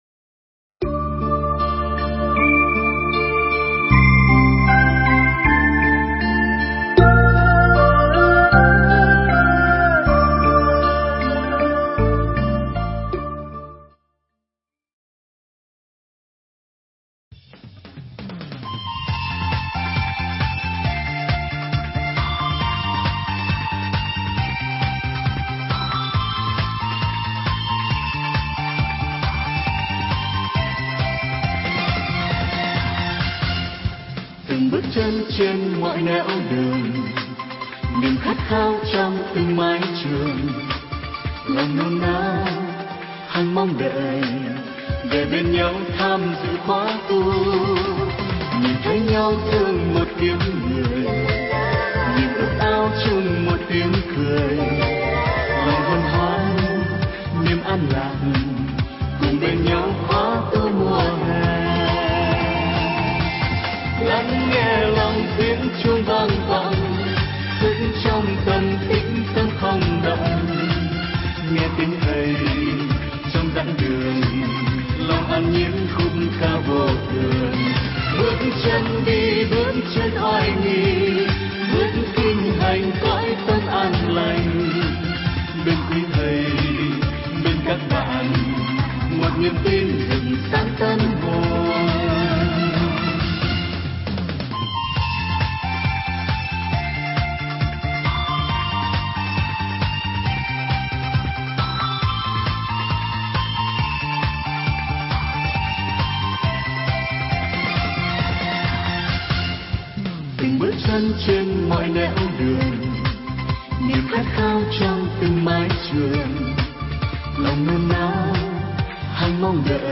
Mp3 thuyết pháp